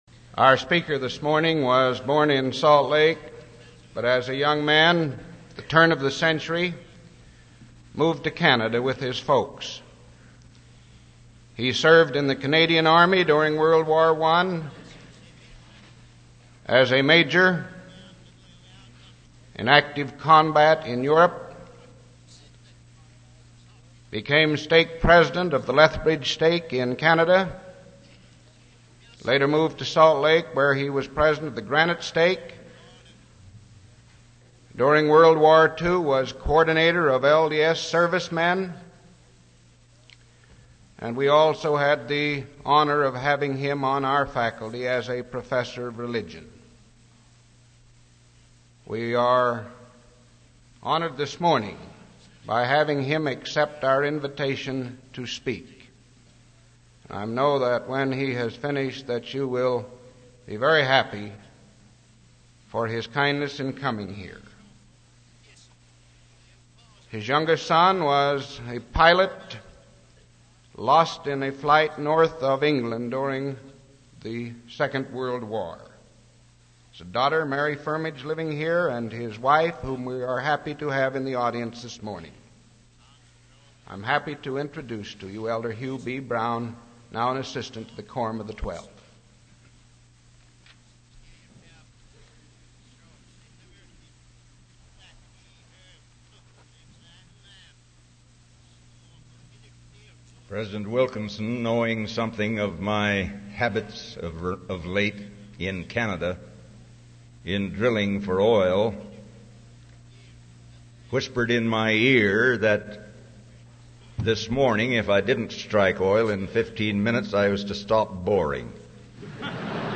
Assistant to the Quorum of the Twelve Apostles
Click to copy link Speech link copied Devotional Why Religious Education!